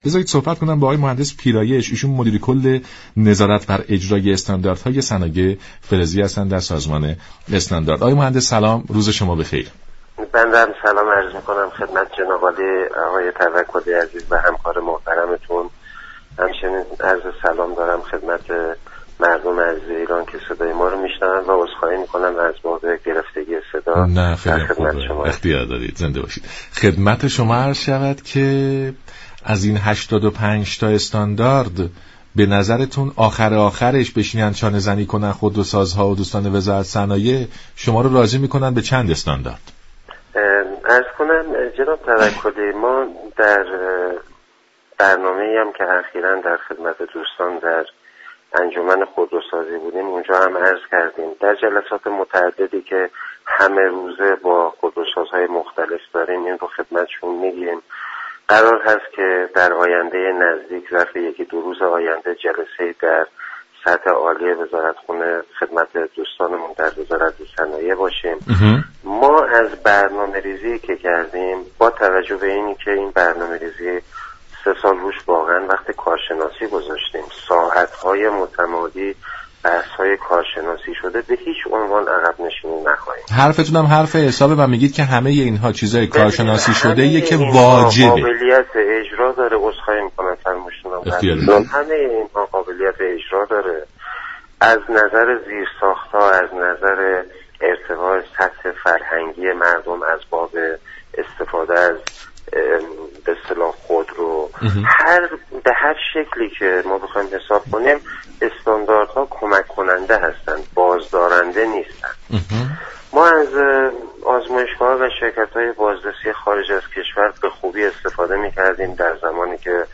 مدیر كل نظارت بر اجرای استاندارهای صنایع فلزی در سازمان استاندارد در گفت و گو با برنامه «نمودار» گفت: خودورسازان ایرانی برای تولید خودرو ملزم به دریافت 52 نشانه استاندارهستند؛ سازمان استاندارد پیگیر جدی این ماجرا است.